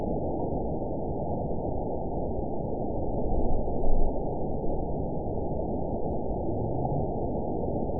event 912046 date 03/17/22 time 01:07:23 GMT (3 years, 2 months ago) score 9.10 location TSS-AB01 detected by nrw target species NRW annotations +NRW Spectrogram: Frequency (kHz) vs. Time (s) audio not available .wav